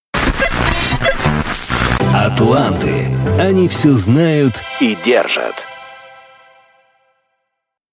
джинглы